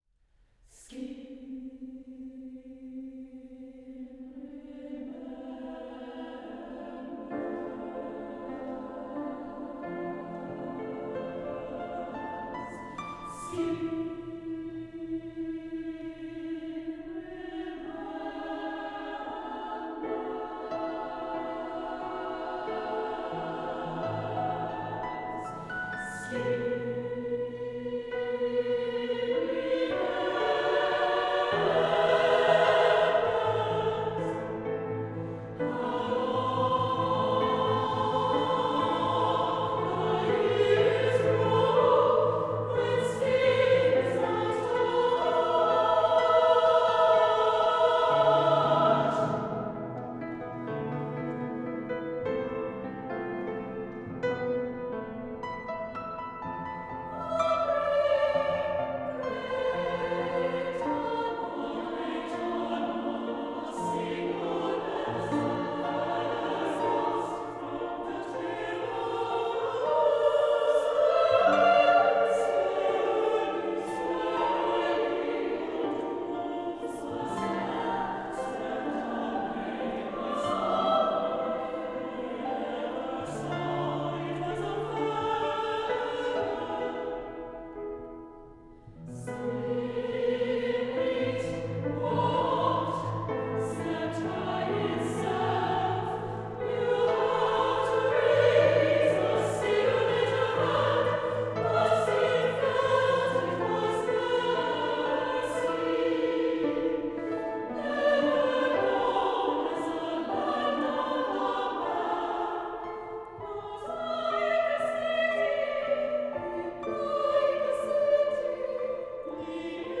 for SSAA chorus and piano